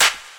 • 2000s Short Acoustic Snare Sample F Key 05.wav
Royality free acoustic snare sample tuned to the F note. Loudest frequency: 3938Hz